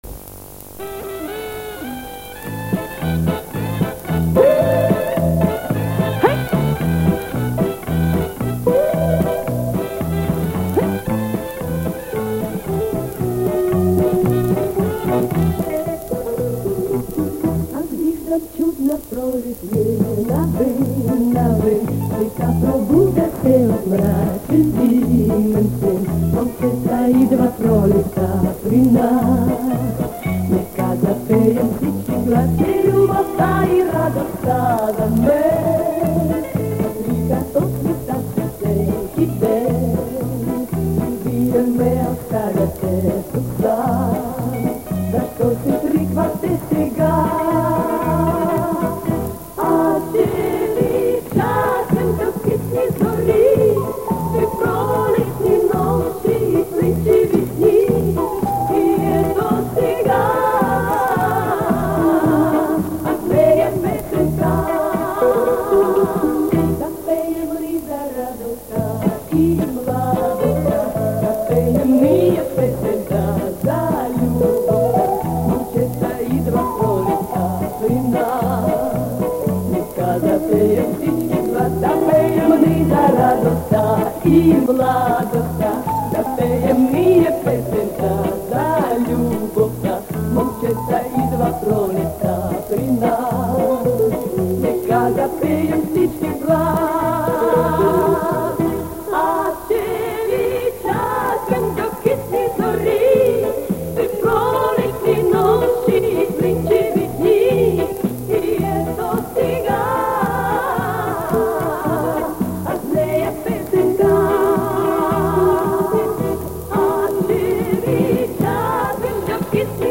Все они с катушек магнитных лент, записанные в 70е года.